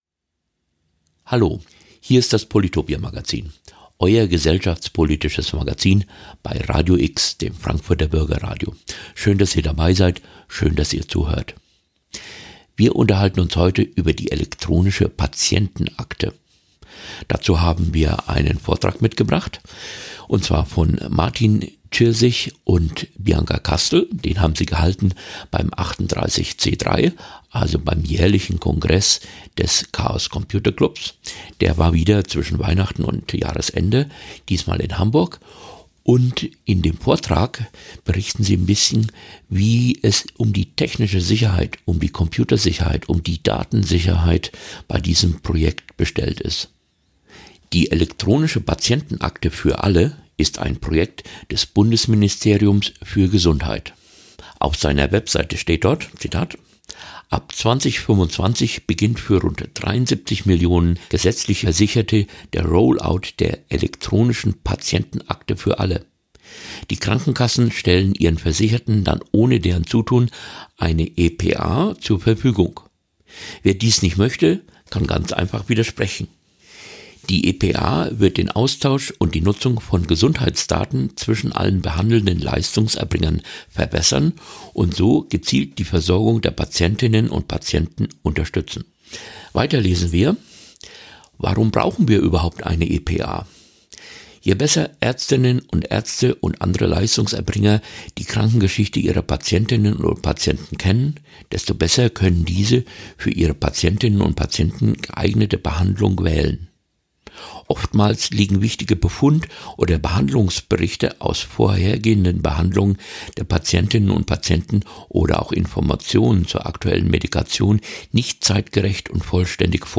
Wir hören einen Vortrag zur Sicherheit der elektronischen Patientenakte, die ab Januar ausgerollt werden soll.